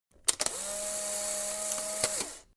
音效类别：机械
• 键盘打字声